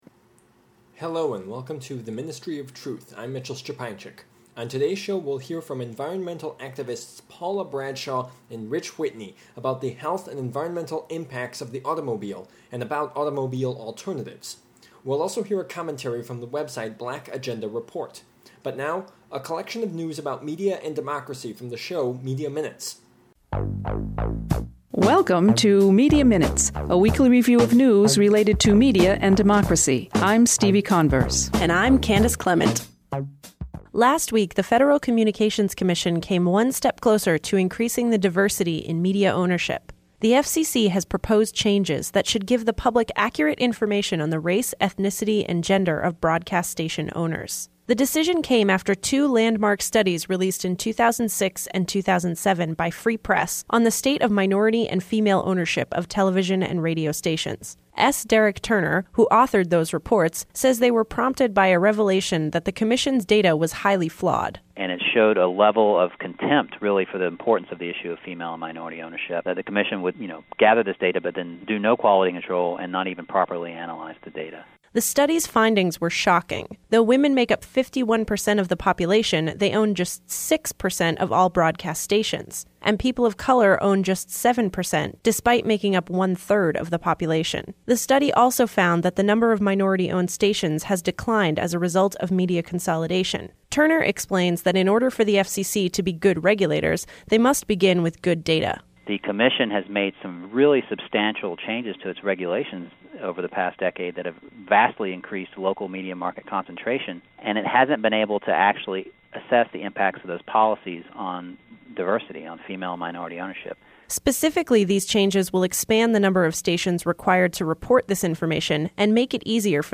The Ministry of Truth: Presentation
Tags: radio